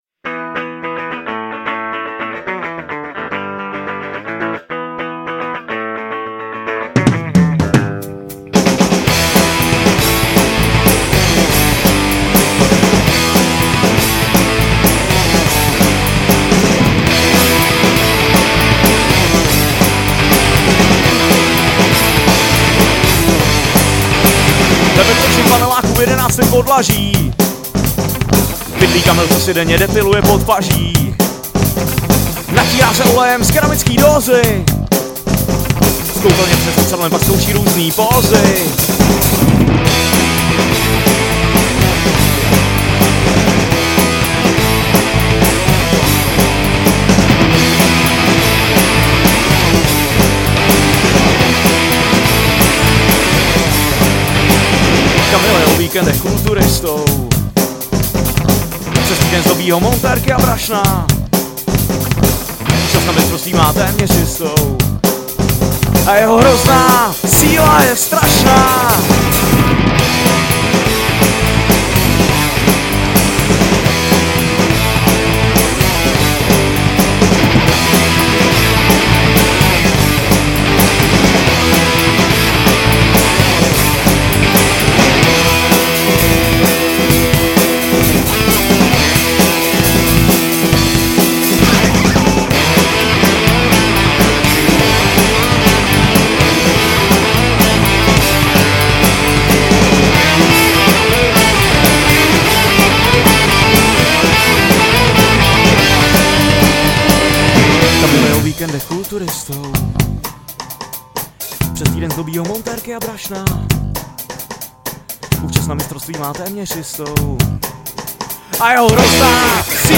Žánr: Punk
punkrockové kapely